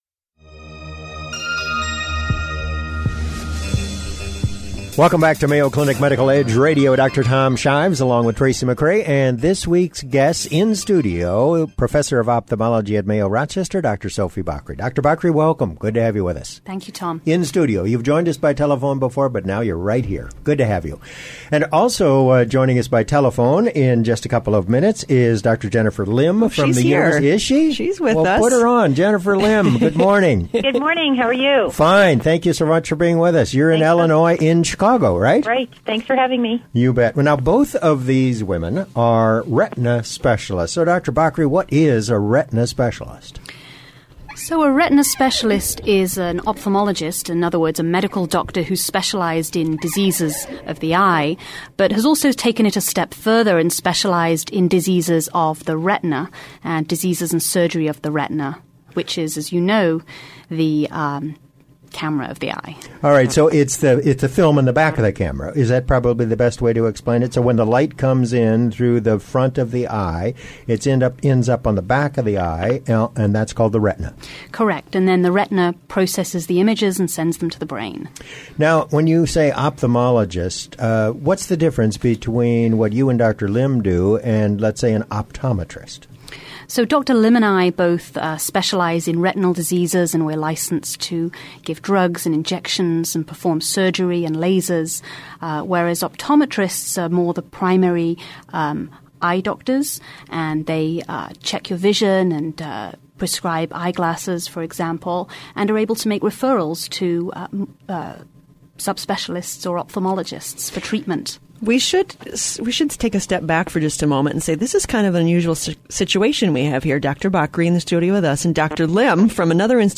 To listen to the radio program in its entirety, visit Medical Edge Radio .